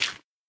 sounds / dig / gravel4.ogg
gravel4.ogg